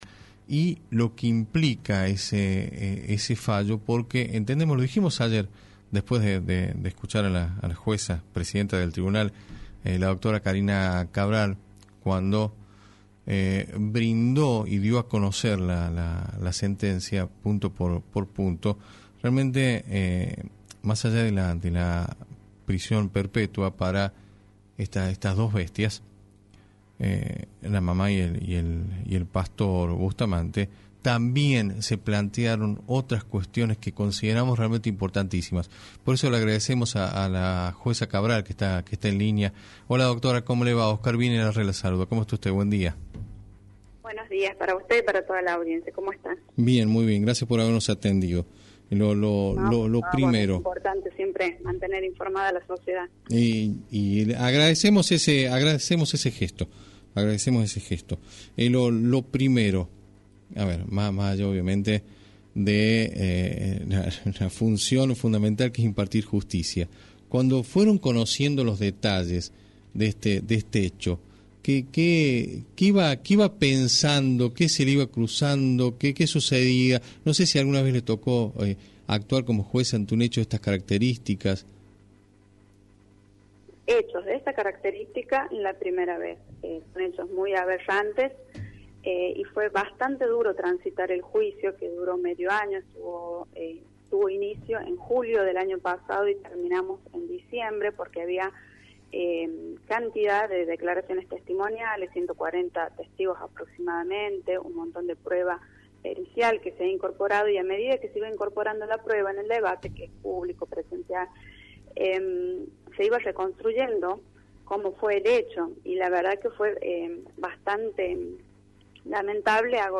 En diálogo con La Red, la magistrada expresó la dureza emocional que implicó transitar un juicio que se extendió durante medio año, desde julio hasta diciembre del año pasado.